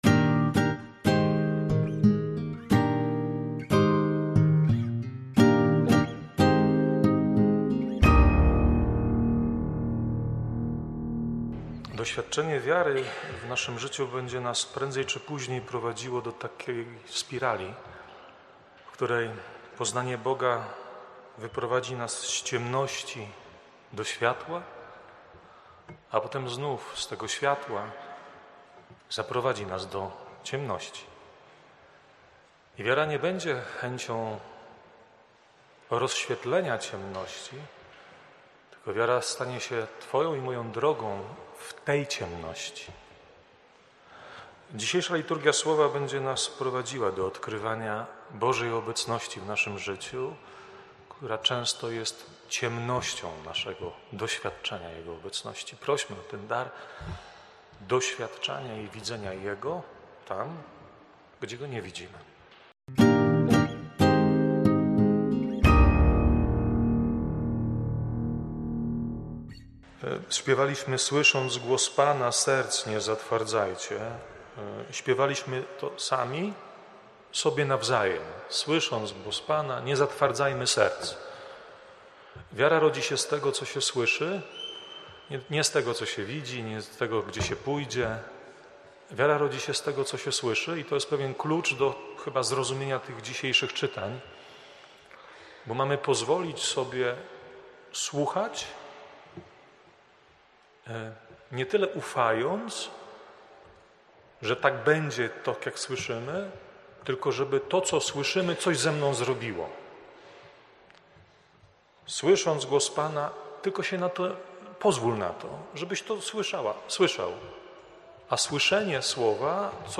kazania.